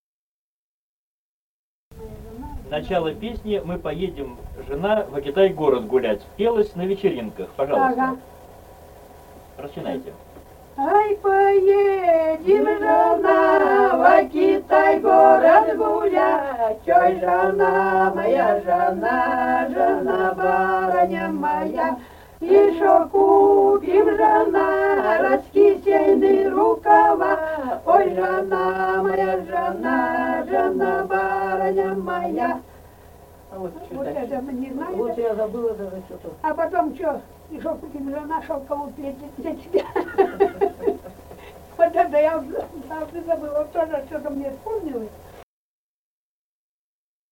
Республика Казахстан, Восточно-Казахстанская обл., Катон-Карагайский район, с. Урыль (казаки), июль 1978.